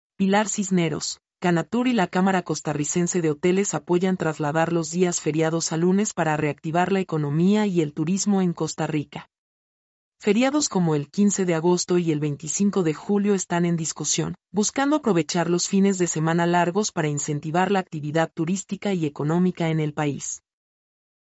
mp3-output-ttsfreedotcom-92-1.mp3